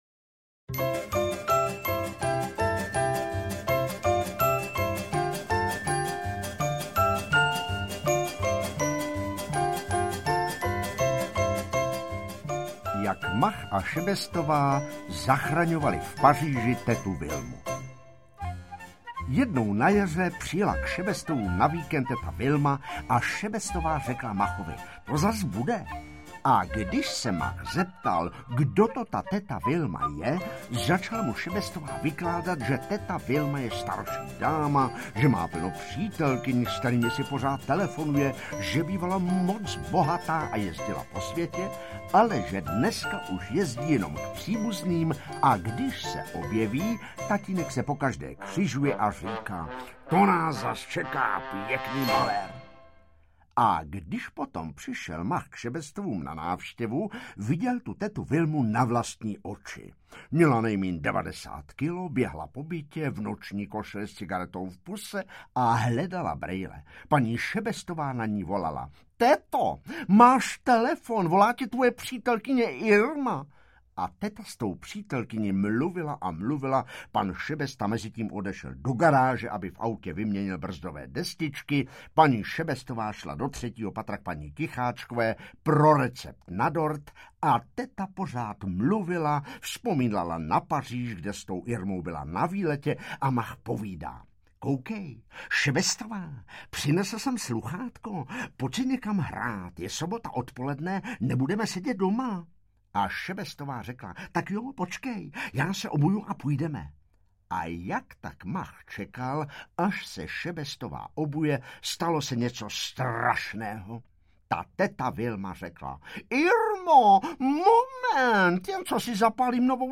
Interpret:  Petr Nárožný
AudioKniha ke stažení, 6 x mp3, délka 1 hod. 14 min., velikost 67,0 MB, česky